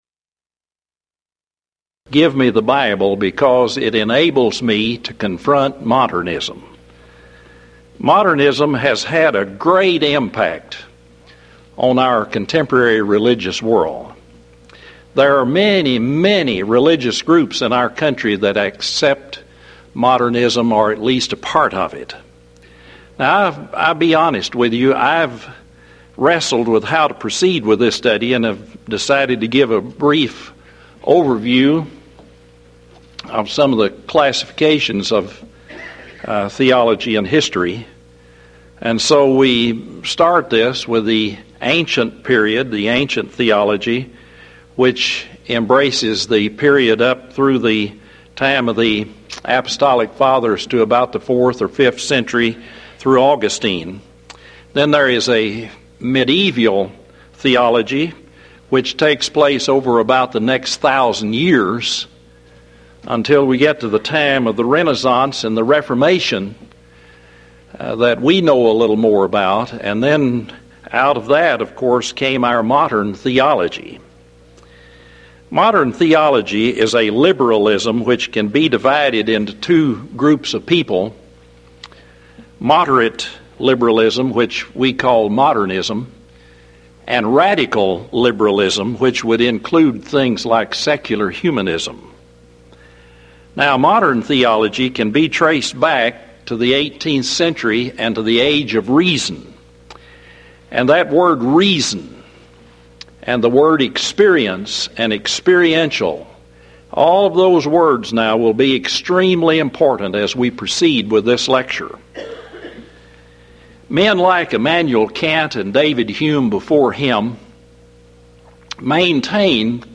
Event: 1996 Mid-West Lectures